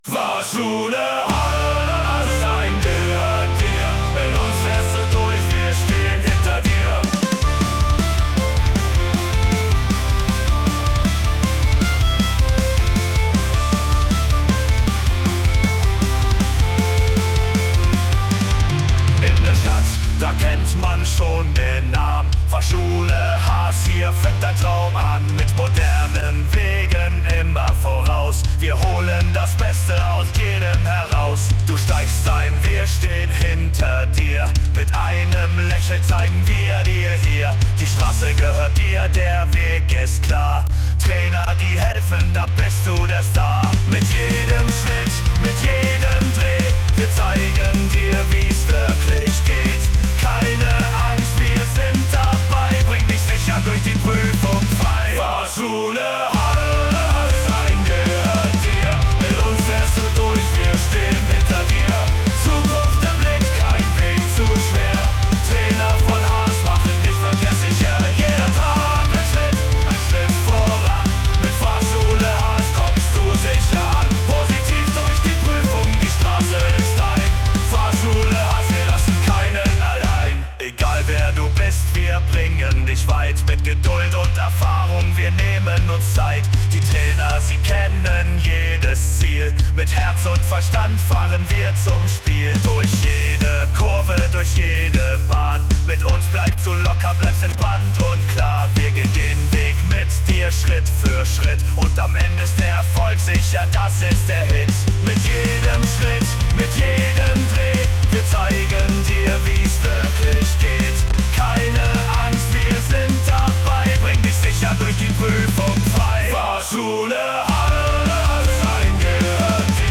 Volle Lautstärke für unseren Fahrschul-Song!
So rockt nur die Fahrschule Haas!